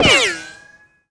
RICOCHET.mp3